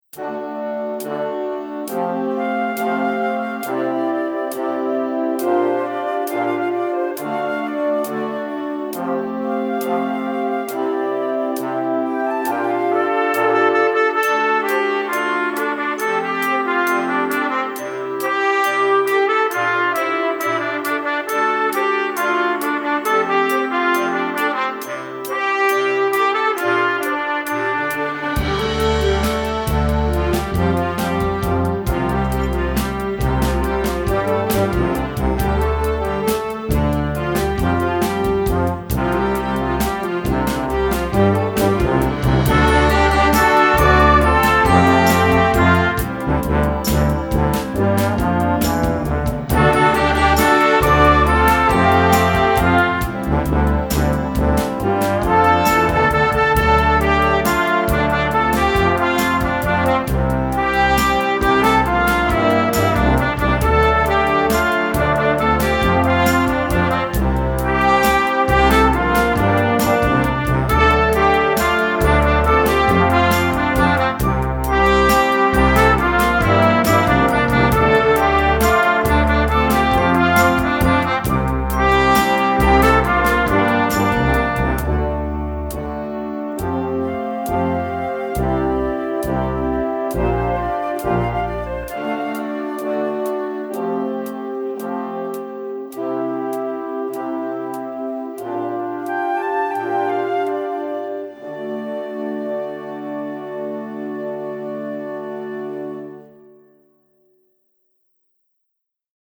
Moderner Einzeltitel für Jugendblasorchester
Besetzung: Blasorchester